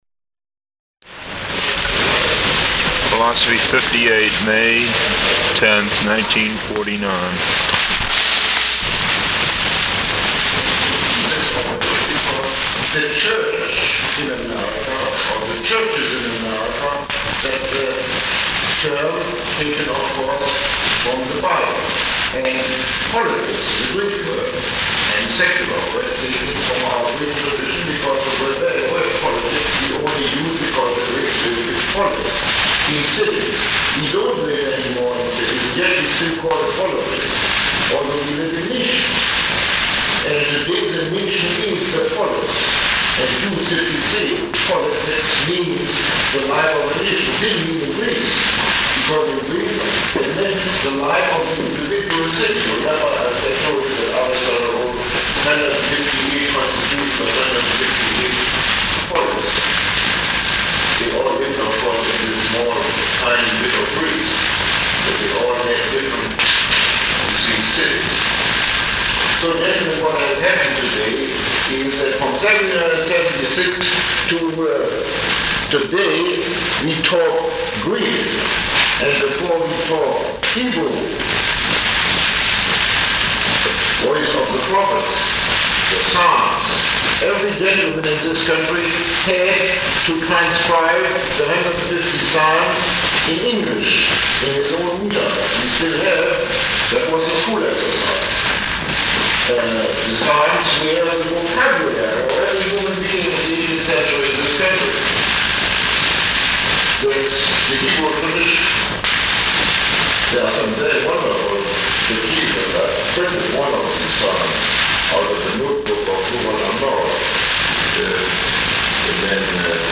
Lecture 1